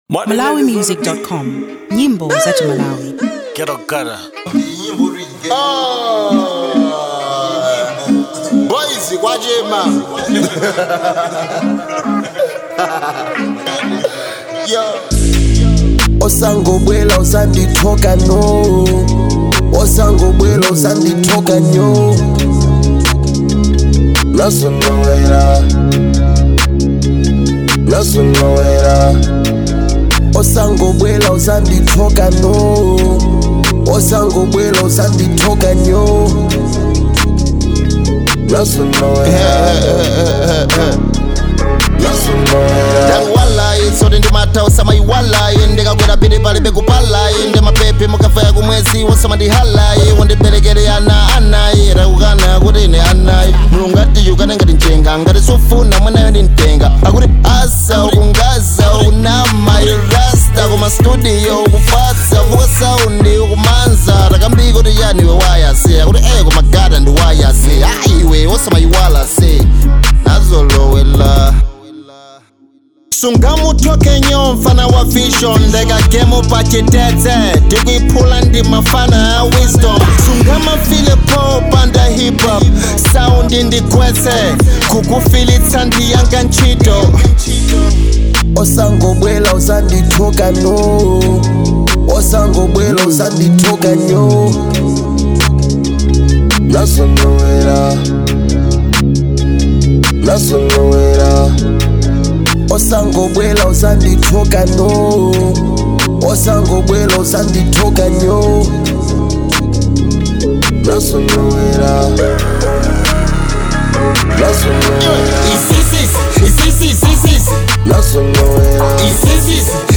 • Genre: Hip Hop